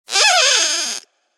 squeak.ogg.mp3